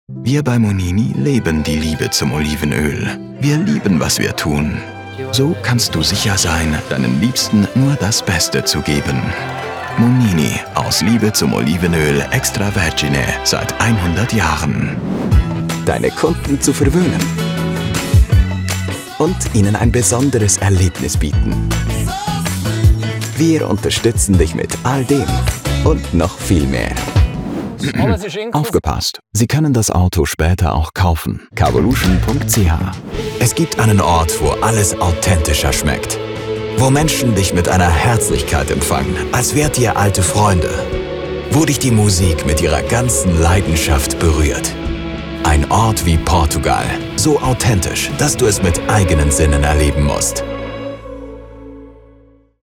German with a Swiss German Accent Reel
An authentic, real narrative voice.
Hochdeutsch mit Akzent Demo REEL 2026.mp3